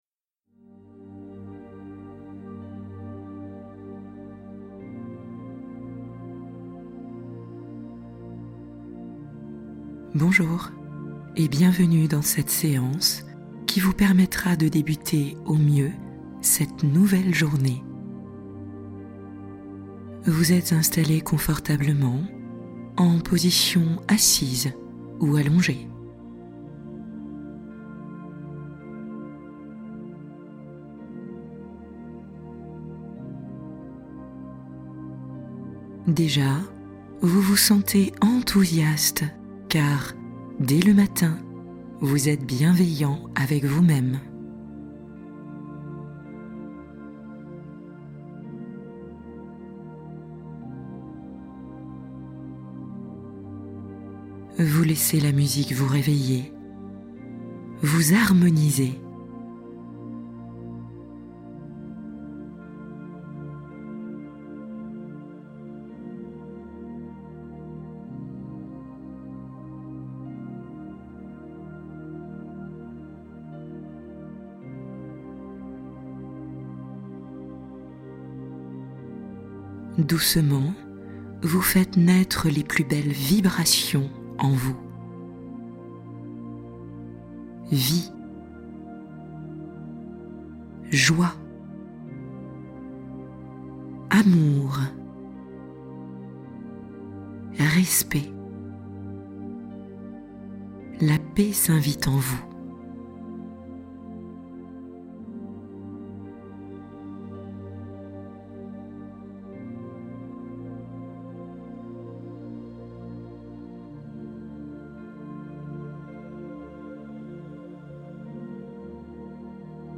Douceur matinale : Commencez votre journée comme une caresse | Méditation réveil en paix